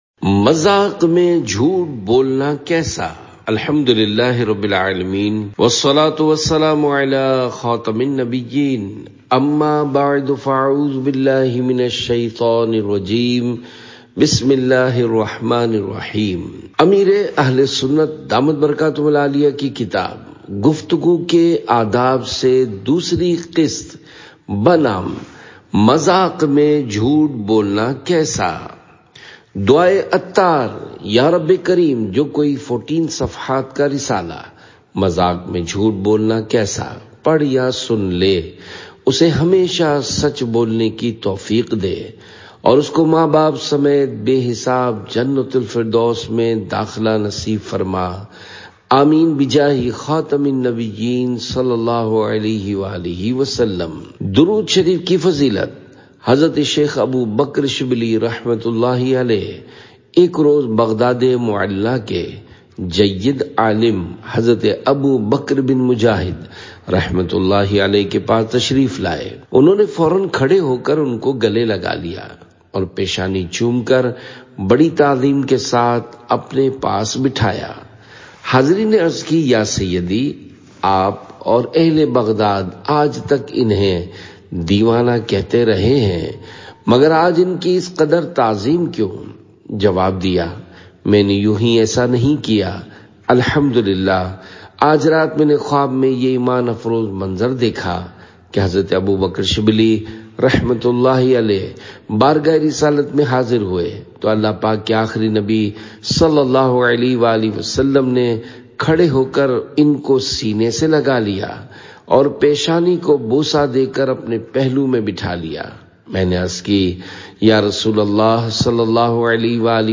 Audiobook – Mazaq Me Jhoot Bolna Kaisa? (Urdu)
آڈیو بک – مذاق میں جھوٹ بولنا کیسا؟